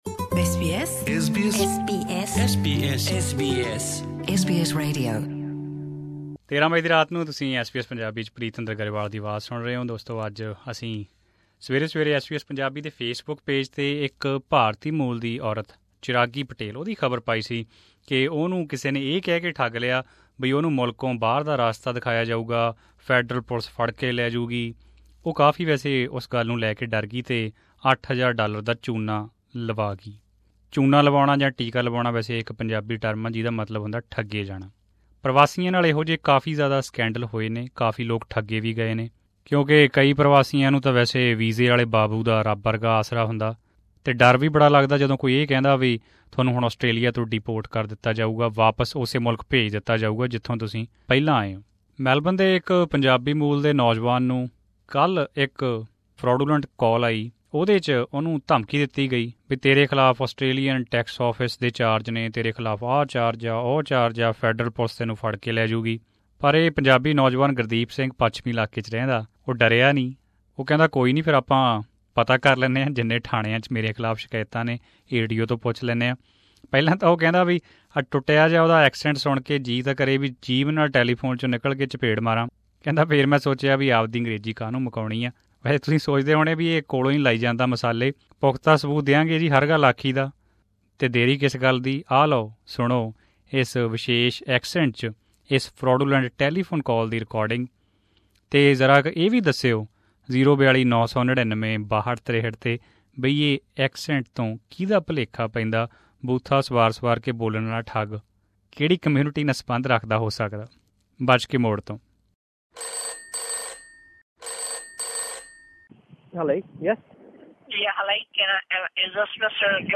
phone call audio included